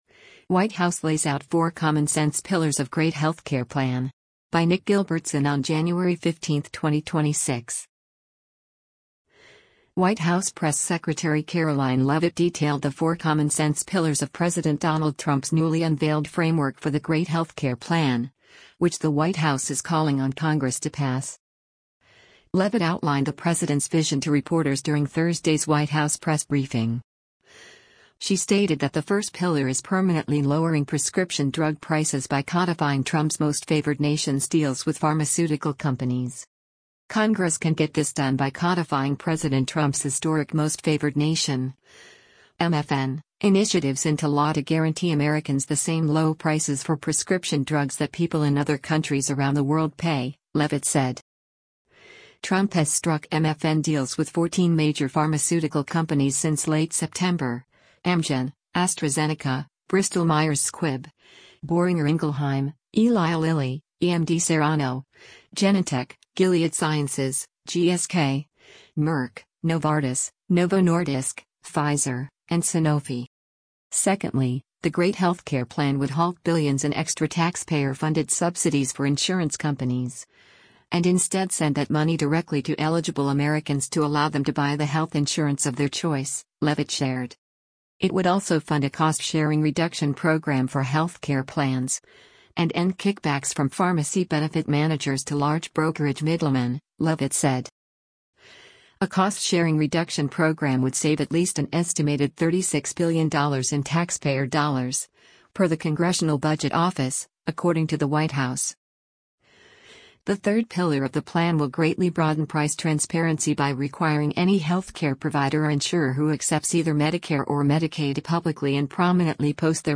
Leavitt outlined the president’s vision to reporters during Thursday’s White House press briefing.